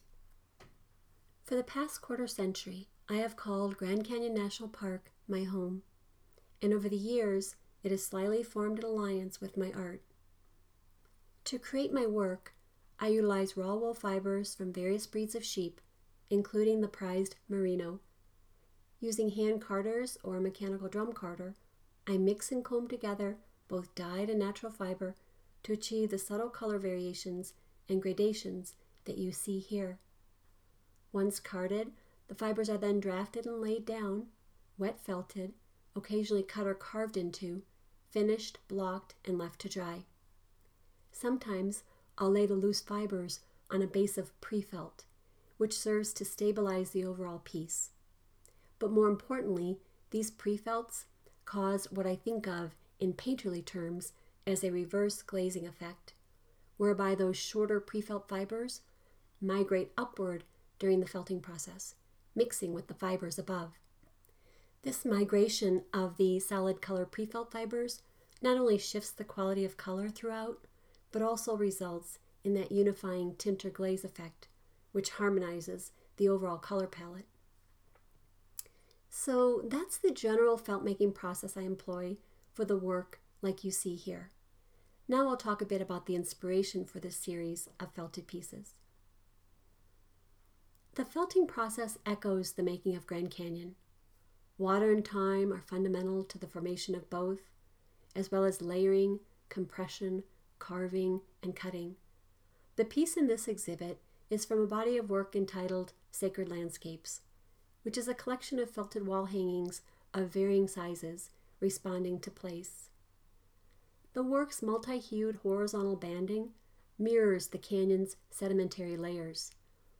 Audio Description of "Sacred Landscape" Series